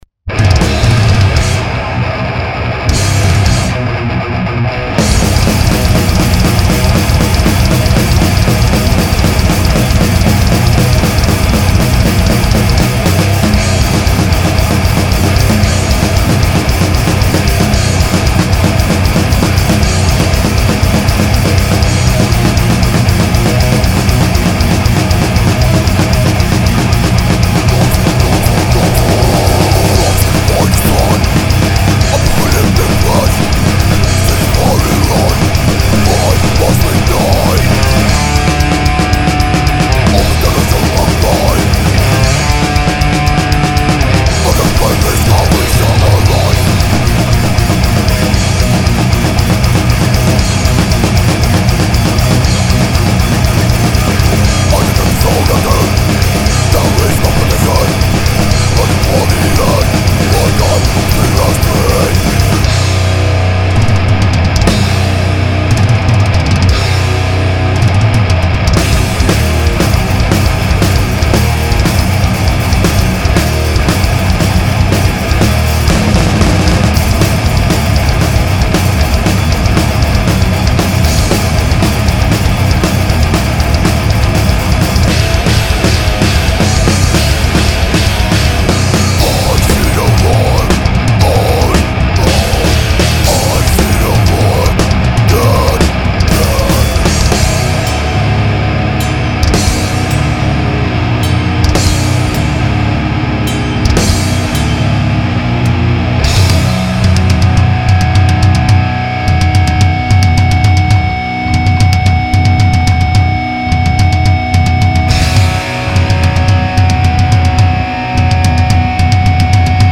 gitár